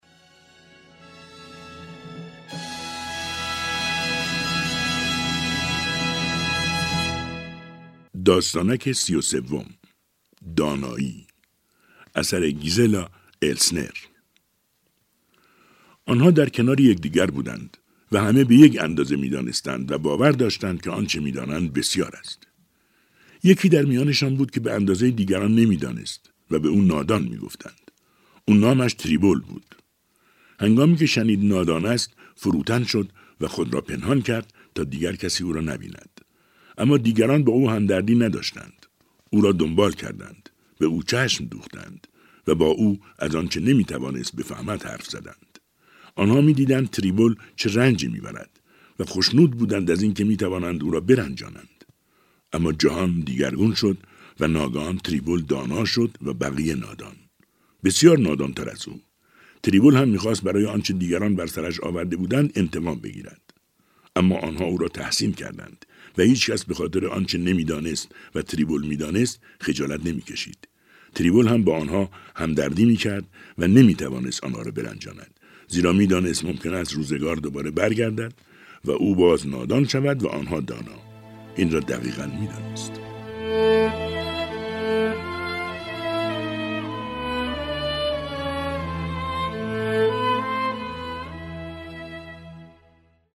۴۴ داستانک با صدای بهروز رضوی بازخوانی می‌شود + صوت
چهل و چهار داستانک از نویسندگان مشهور جهان در برنامه «کتاب شب» رادیو تهران با صدای بهروز رضوی، از روز شنبه (۲۸ تیر ماه) تا پنجشنبه (۲ مرداد ماه)، بازخوانی خواهد شد.